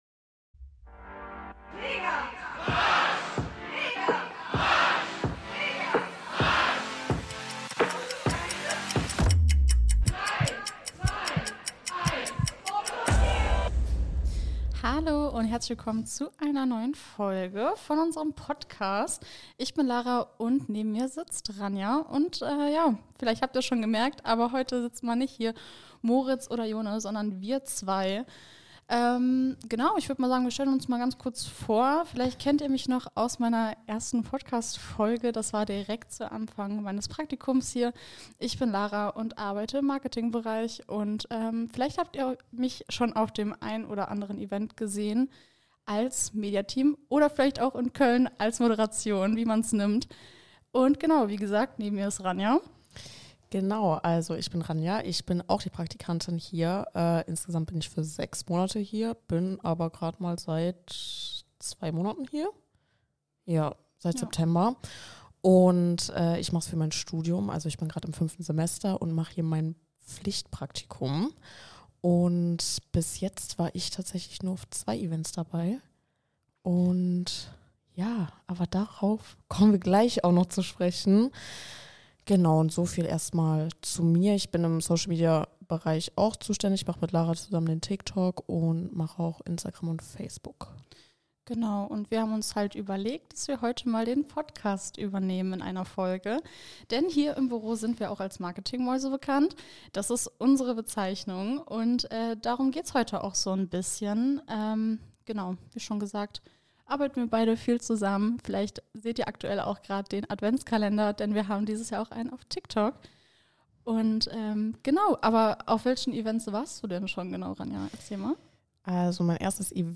Zum Abschluss erwartet euch noch ein Überraschungsgast, der die Folge perfekt abrundet.